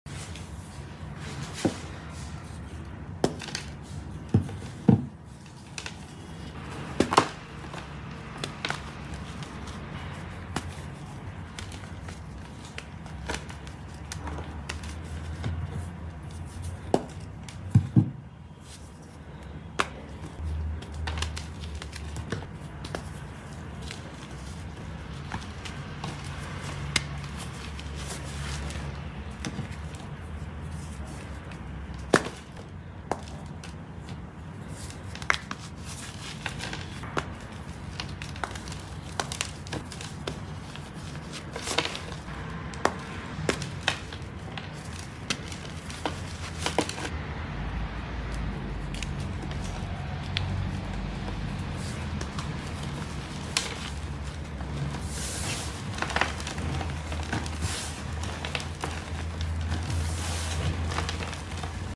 Long Chalk Rod